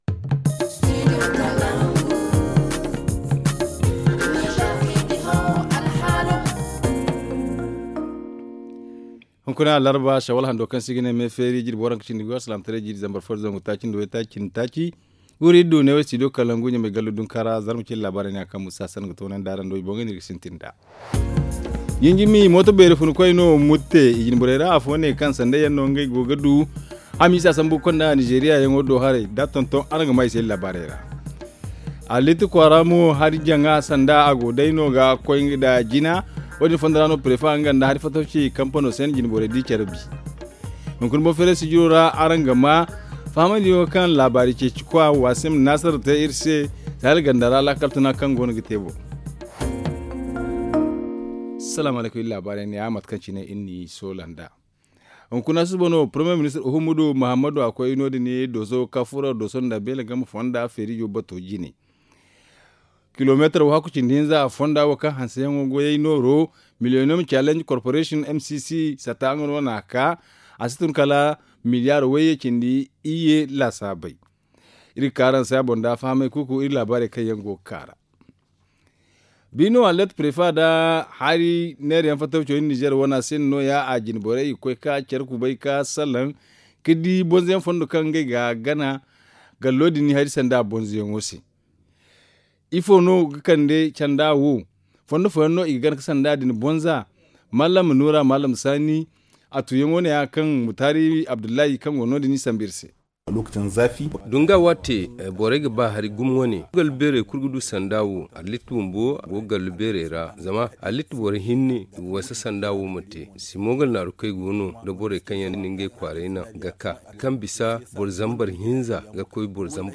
Le journal du 17 mai 2023 - Studio Kalangou - Au rythme du Niger